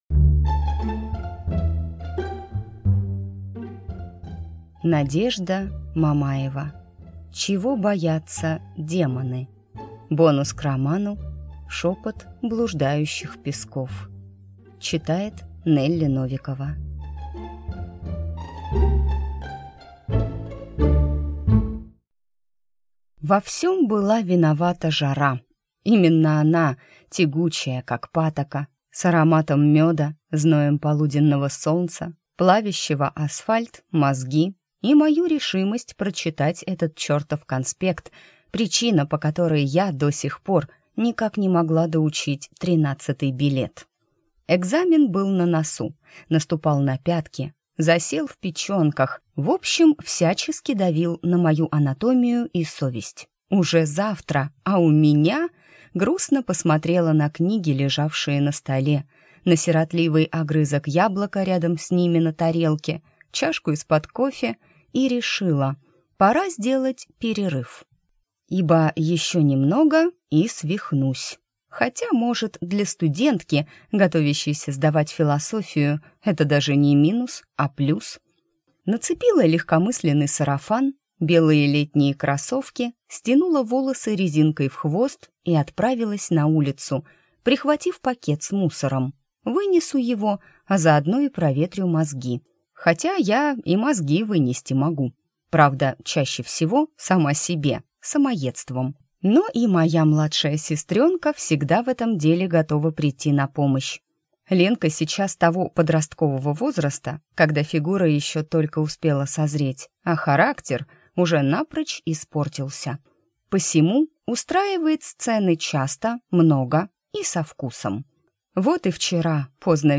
Аудиокнига Чего боятся демоны | Библиотека аудиокниг